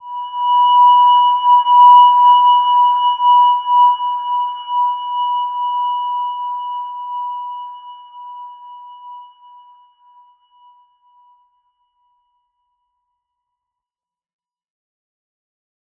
Silver-Gem-B5-mf.wav